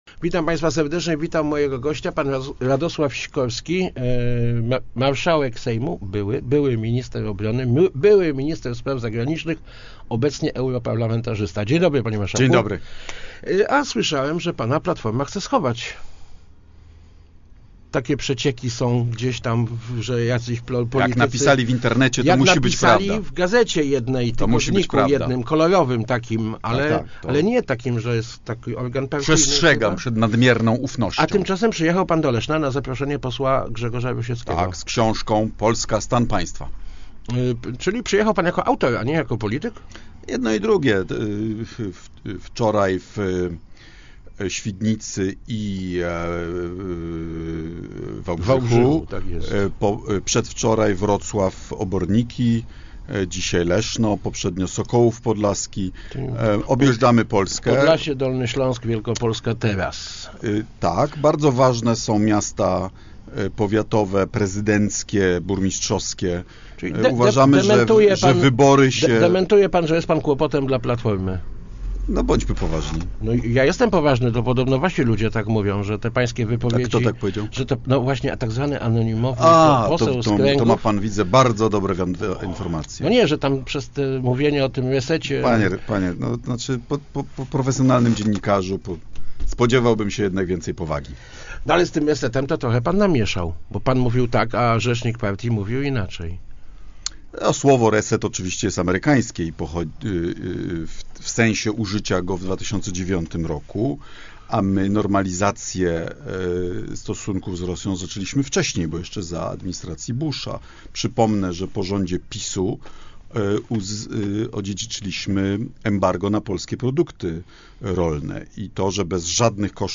Były Marszałek Sejmu w Radiu Elka. Sikorski: reset z Rosją był dobry